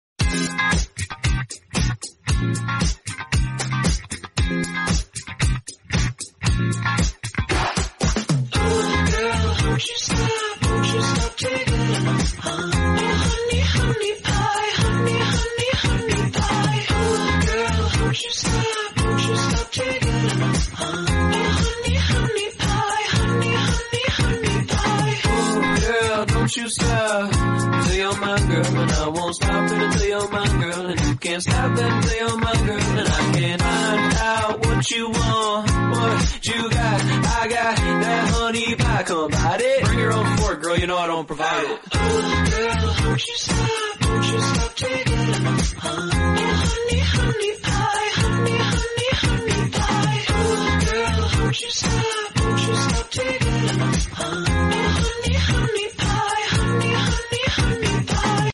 I'm so sorry the quality is so bad..😭💔